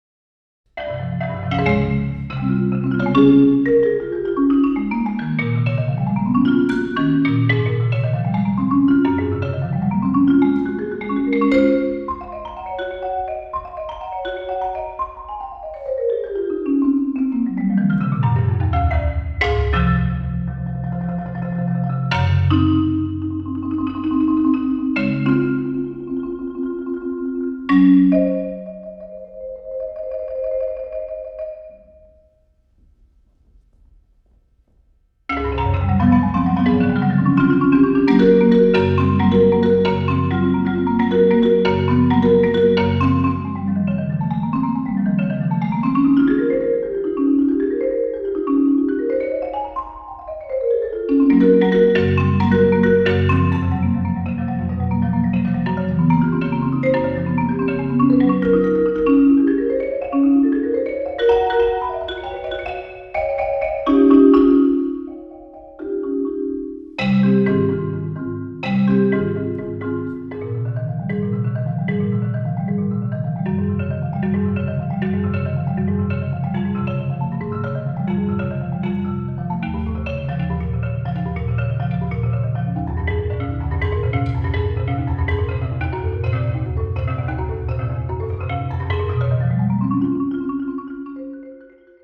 for Solo Marimba
A challenging recital work scored for five-octave marimba.